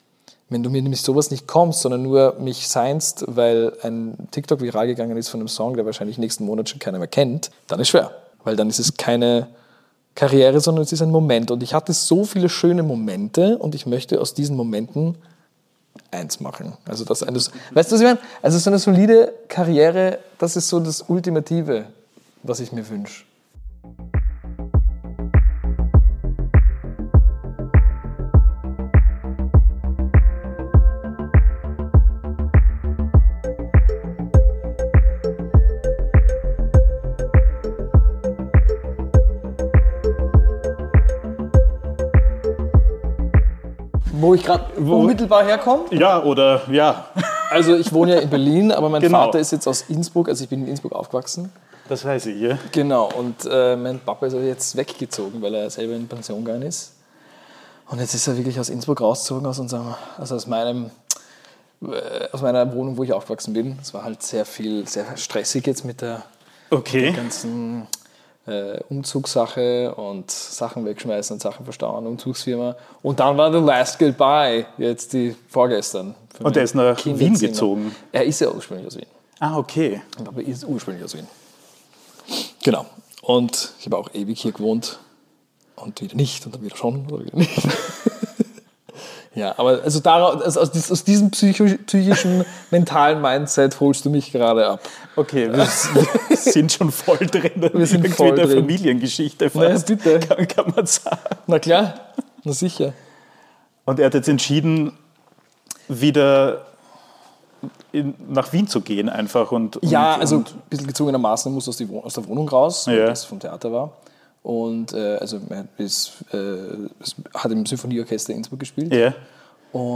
Im ersten Teil unseres Gesprächs erzählt er, warum die Zeit in London für ihn so schwierig war, weshalb er nun in Berlin ein Zuhause gefunden hat und wie er heute auf seine ESC-Erfahrung blickt. Nathan spricht über seine Anfänge in der Musik, den Moment, in dem er wusste, dass er eigene Songs schreiben muss, und erklärt, warum er seine Musik als „HyperRnB“ bezeichnet und seine Stimme mehr für ihn ist als nur ein Instrument.